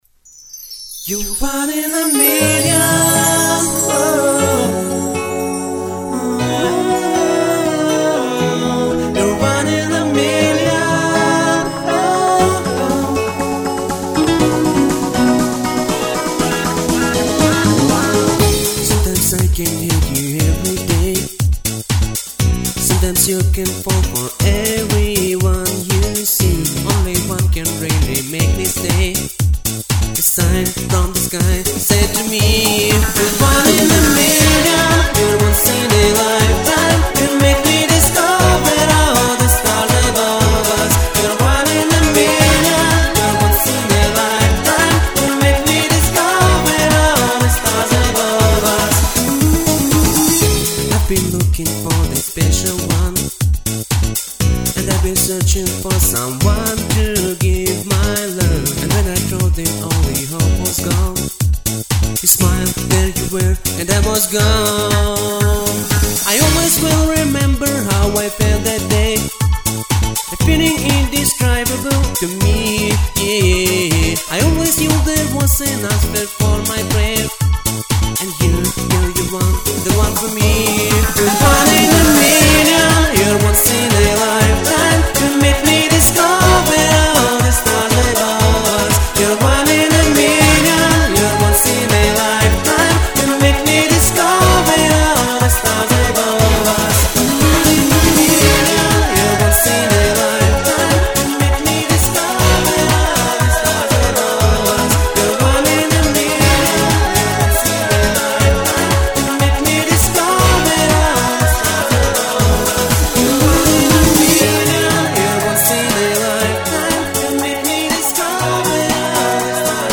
Понравился стерео эффект на второй минуте. klass